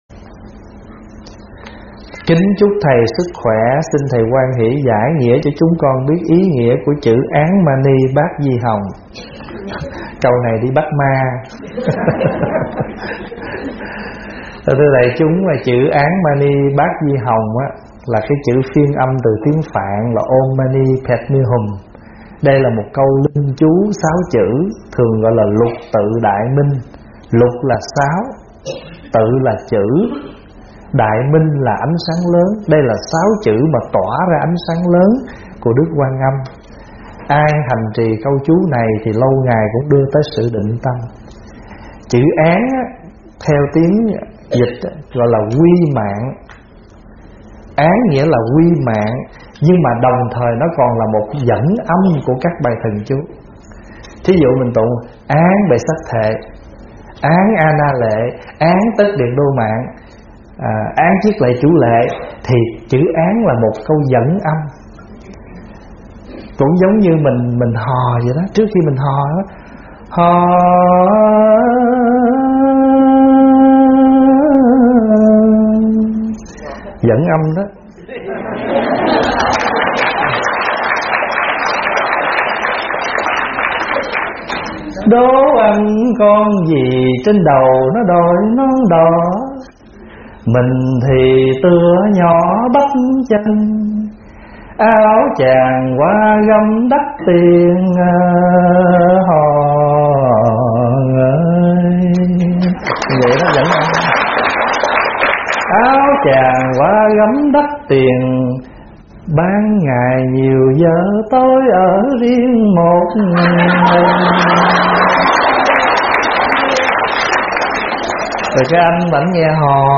Nghe Mp3 thuyết pháp Ý Nghĩa Câu Thần Chú Án Ma Ni Bát Di Hồng - ĐĐ.